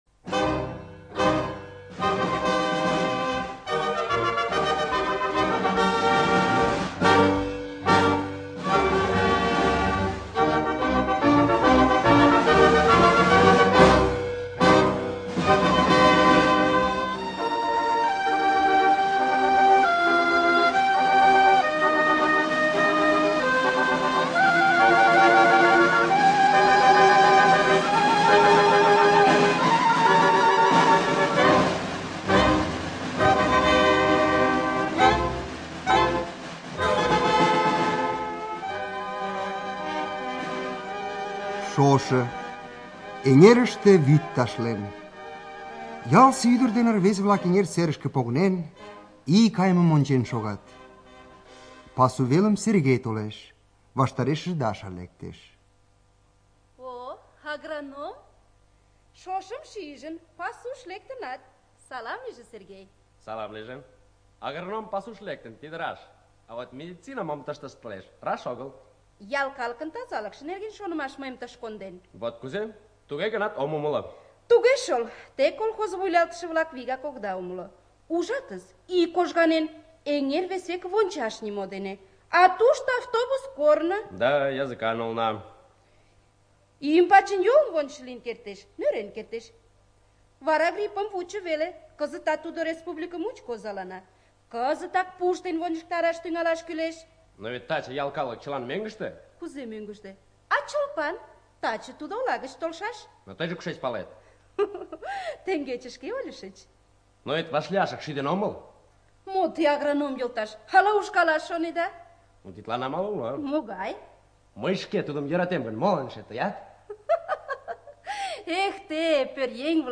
ЖанрРадиоспектакль на языках народов России